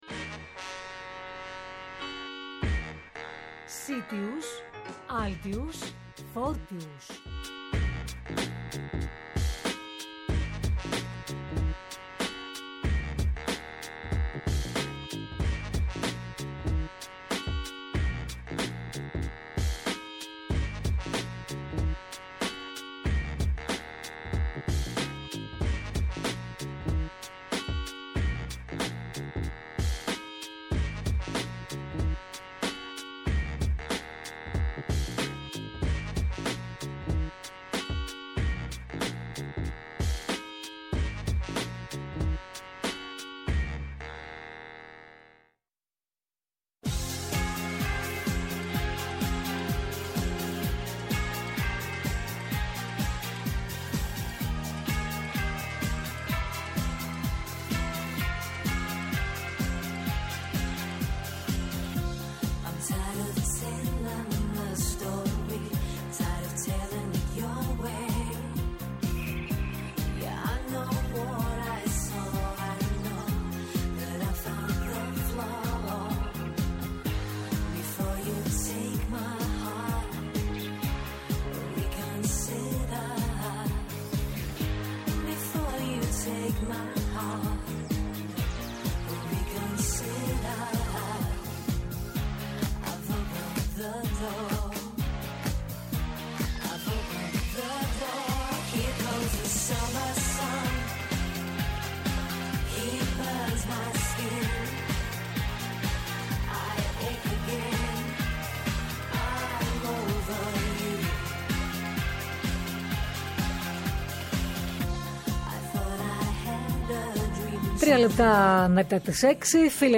Κοντά μας σήμερα, ζωντανά στο στούντιο της ΕΡΑΣΠΟΡ